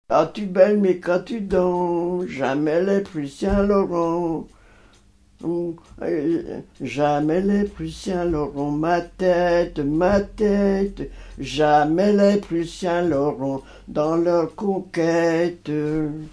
chant de conscrits
Saint-Hilaire-de-Voust
Pièce musicale inédite